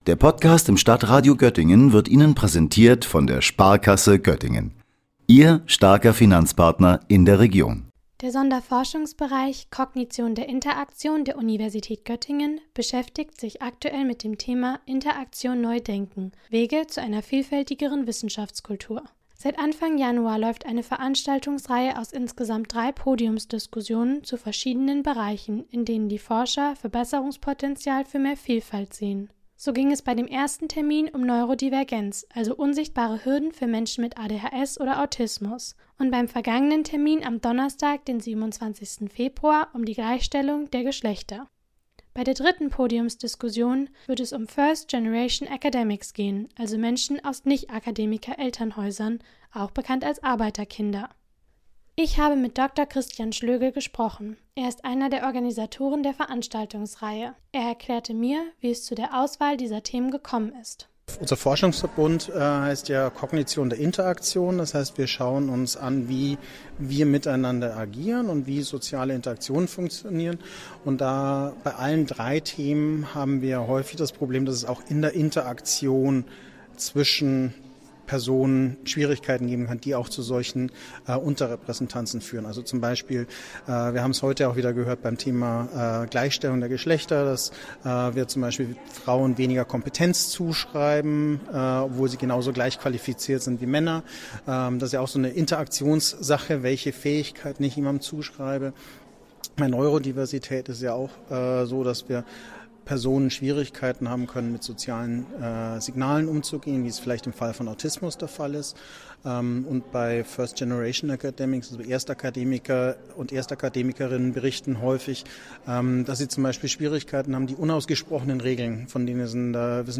hat eine Podiumsdiskussion zu genau diesem Thema besucht und mit den Veranstaltern gesprochen.